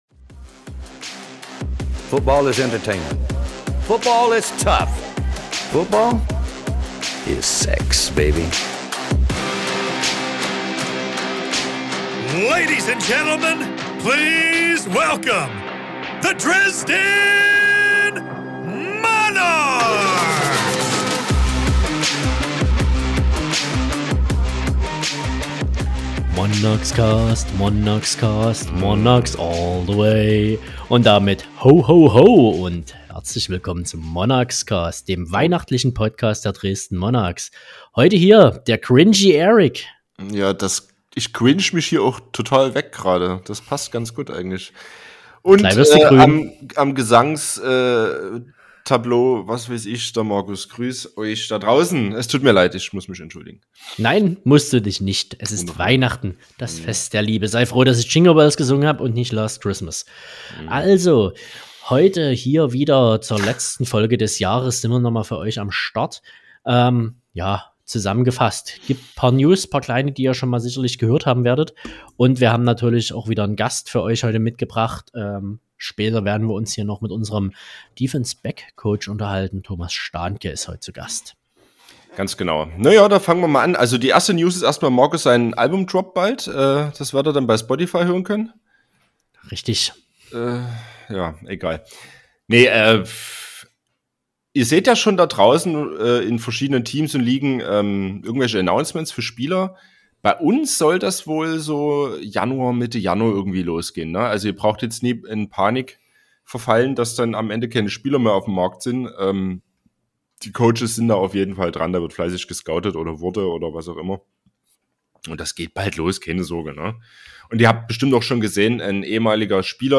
Offseason-Zeit ist Interview-Zeit.